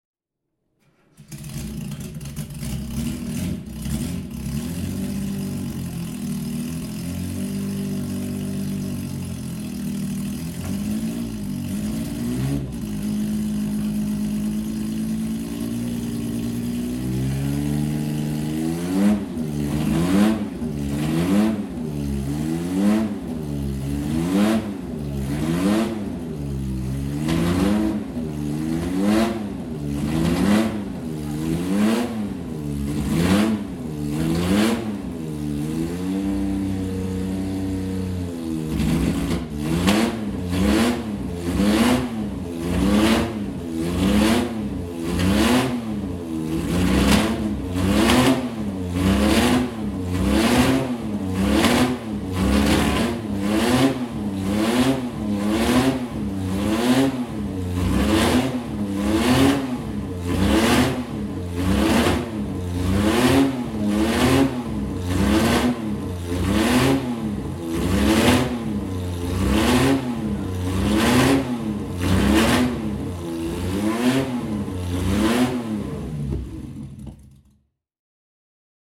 5th Porsche Sound Night 2015 - when racing legends wake up and scream (Event Articles)
Porsche 906 (Carrera 6) (1966) - Laufgeräusch